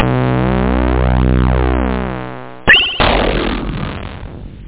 sample07.String.mp3